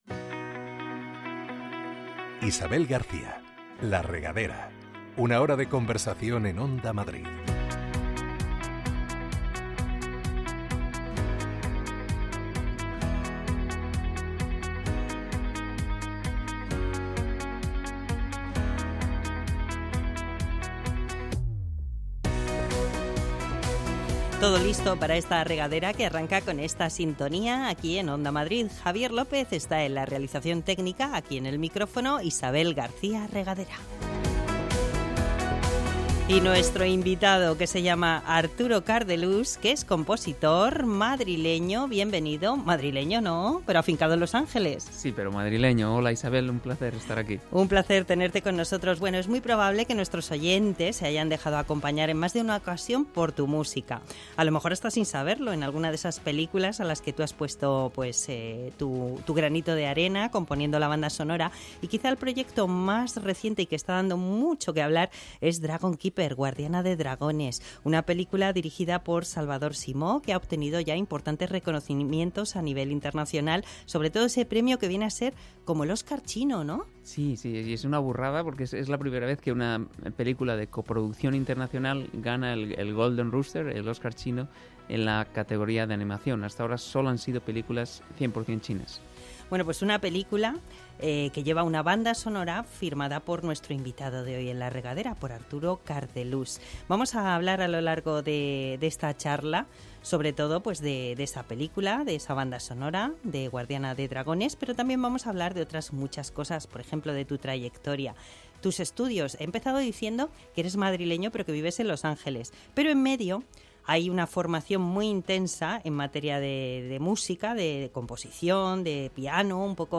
Un espacio para conversar, con buena música de fondo y conocer en profundidad a todo tipo de personajes interesantes y populares.